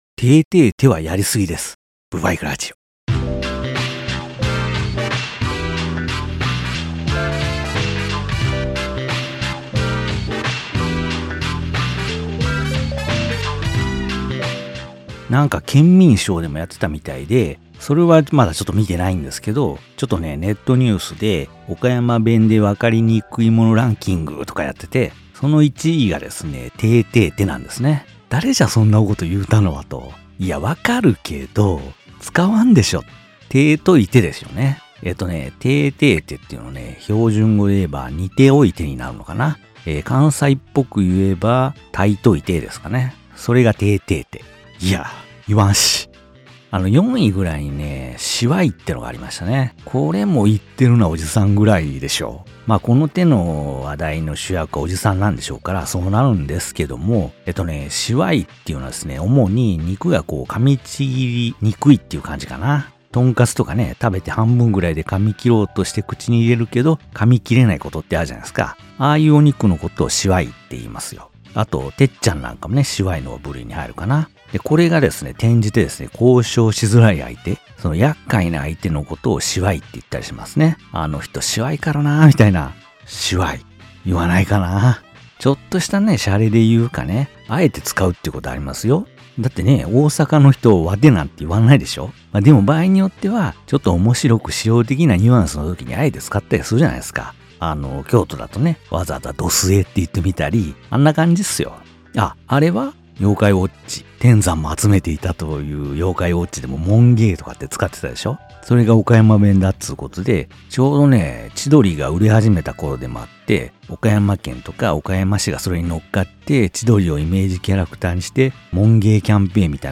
岡山の山奥から何も知らない山男がバイクのある日常について喋っていきます。